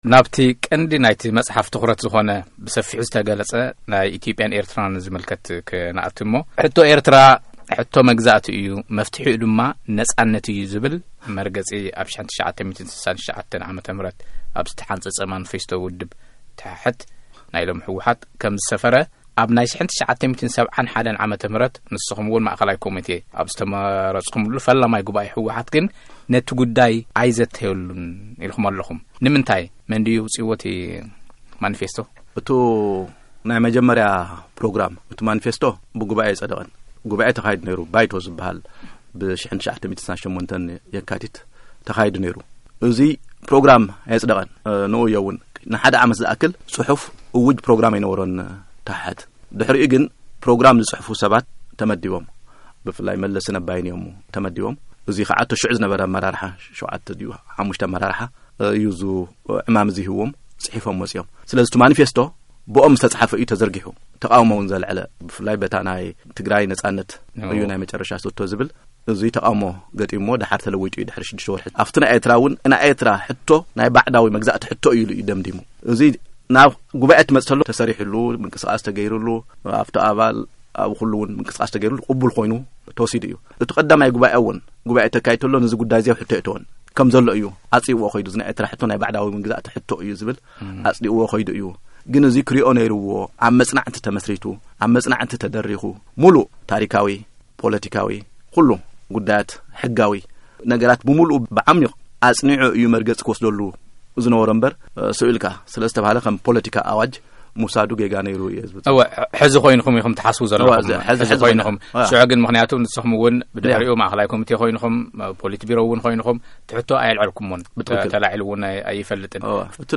ቃለ መጠይቕ ምስ ኣቶ ገብሩ ኣስራት 2ይ ክፋል
ምሉእ ቃለ ምልልስ ምስ ኣቶ ገብሩ ኣስራት